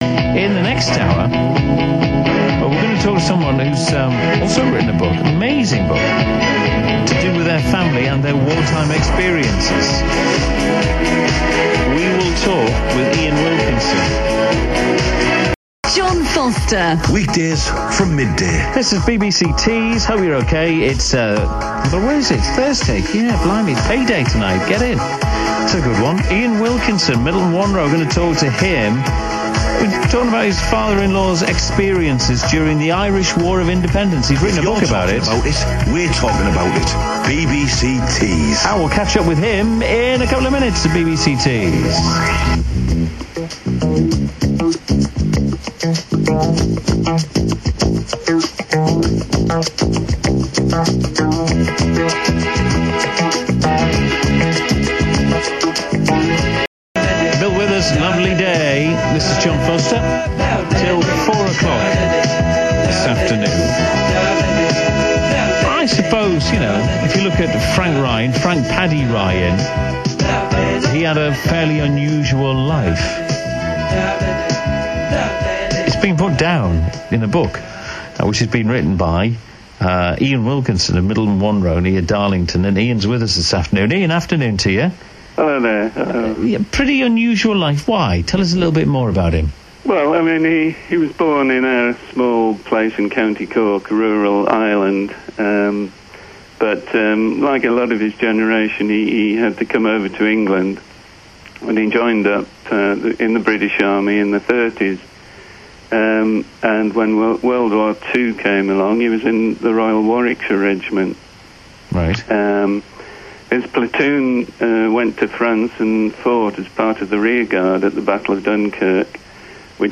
RADIO INTERVIEW:
editedradioiwtees.mp3